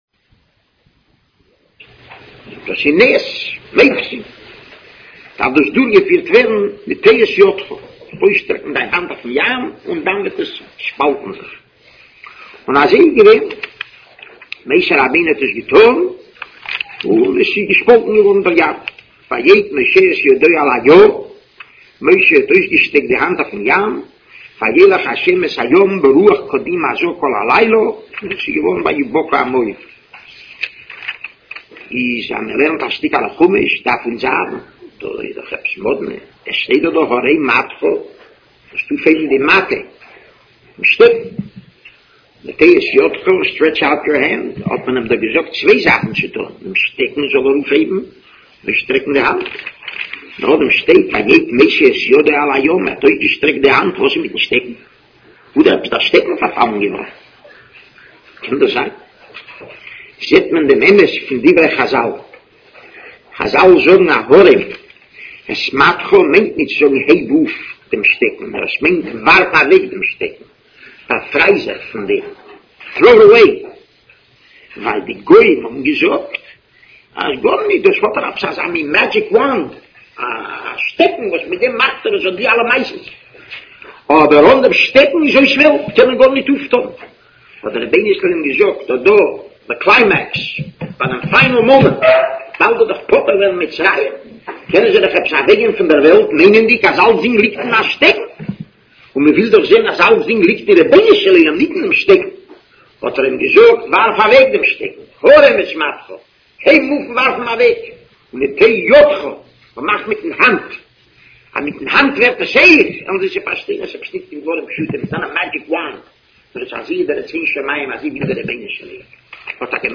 Shiur Daas